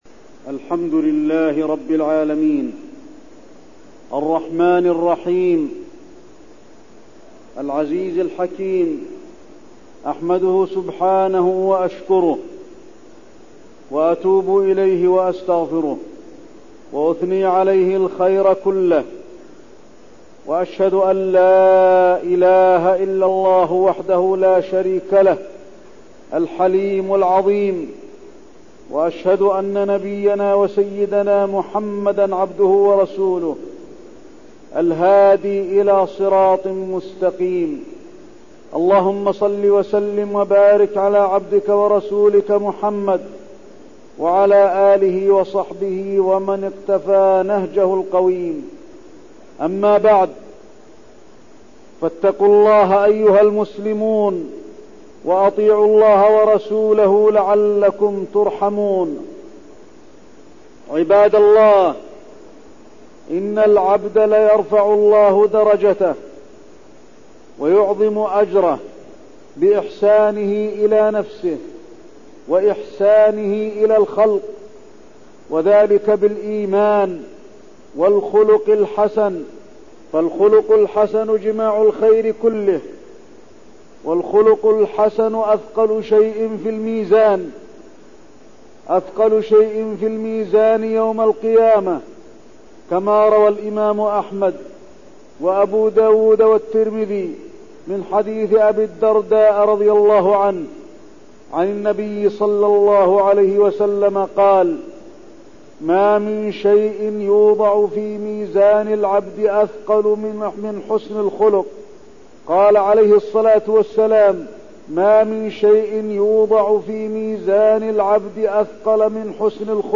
تاريخ النشر ٧ ربيع الأول ١٤١٣ هـ المكان: المسجد النبوي الشيخ: فضيلة الشيخ د. علي بن عبدالرحمن الحذيفي فضيلة الشيخ د. علي بن عبدالرحمن الحذيفي حسن الخلق